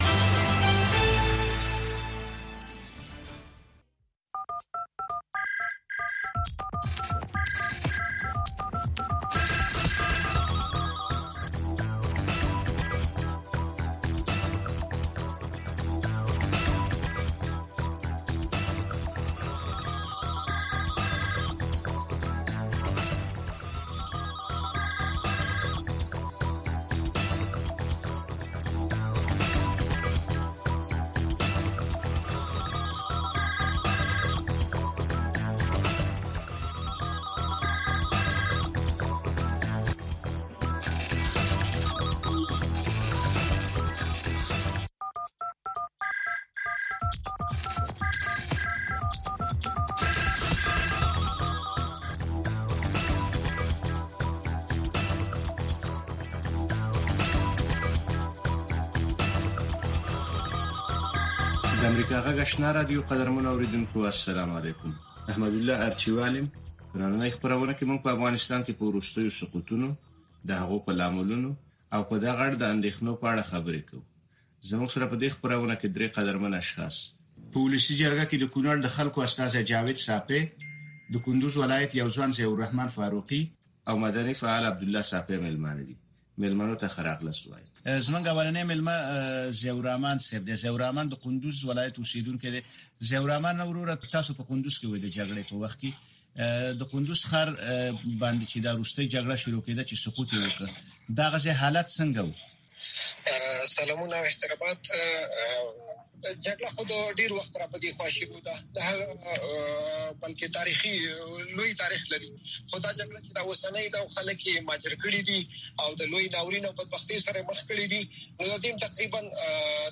خبرې اترې
په دغې خپرونه کې د روانو چارو پر مهمو مسایلو باندې له اوریدونکو او میلمنو سره خبرې کیږي.